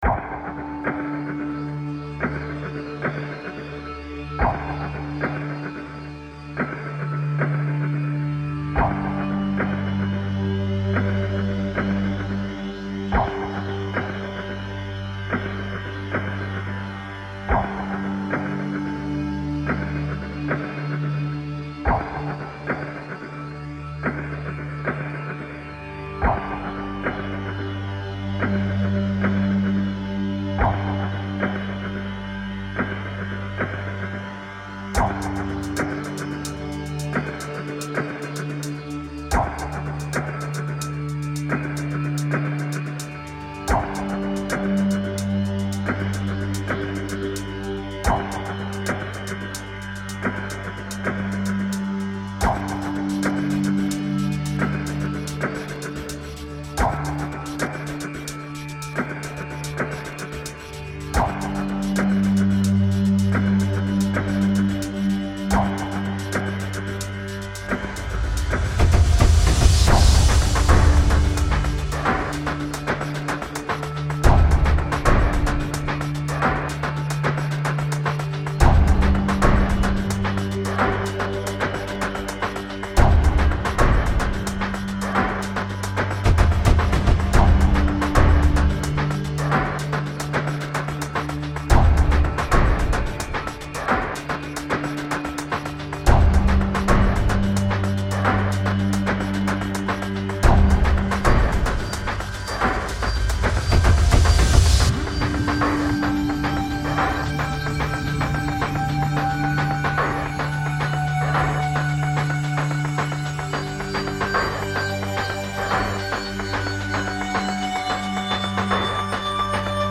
Its a nice music.